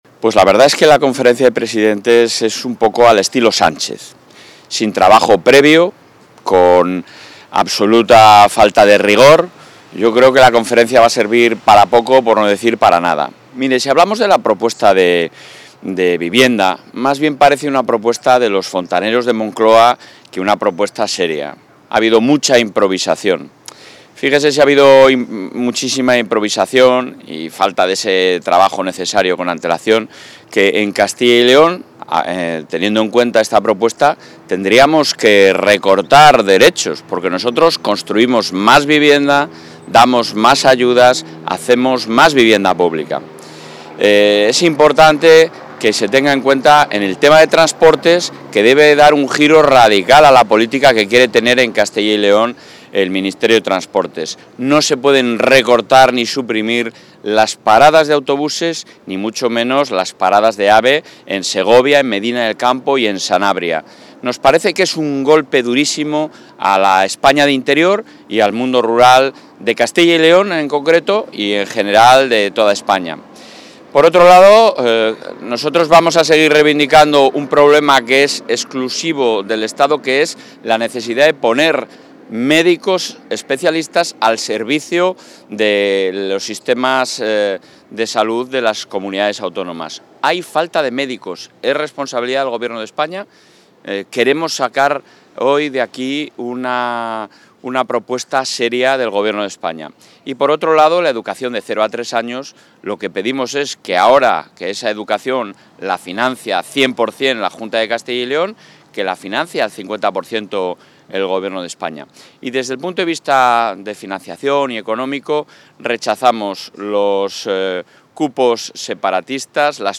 Declaraciones del presidente de la Junta a su llegada a la XXVIII Conferencia de Presidentes
A su llegada al encuentro, ha destacado algunos asuntos de especial interés para la Comunidad y que planteará durante su intervención. Entre ellos, y ante el problema nacional de falta de médicos, el presidente Fernández Mañueco ha reclamado al Gobierno de España que ponga profesionales especialistas a disposición de los Servicios de Salud de las comunidades autónomas.